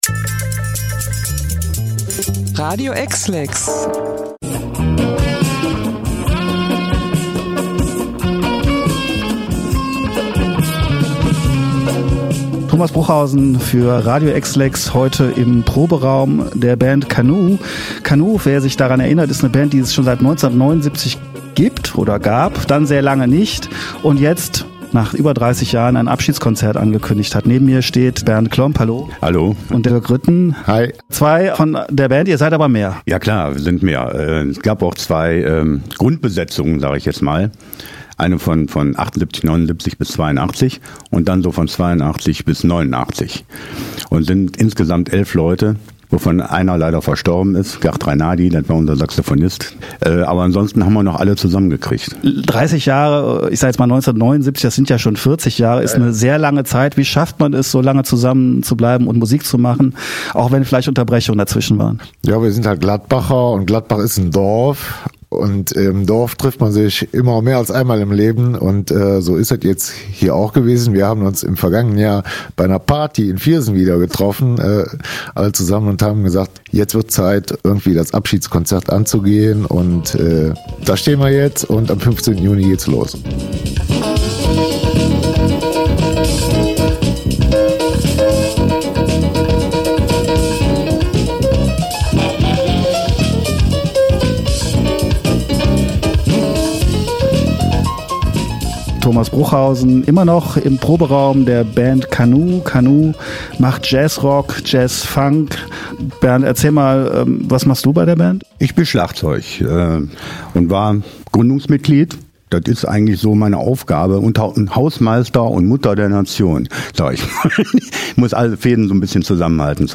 die beiden Musiker im Proberaum zum Interview getroffen
Eine Kostprobe ihrer Jazzkünste gibt’s direkt im Anschluss zu hören – viel Spaß beim Reinhören! https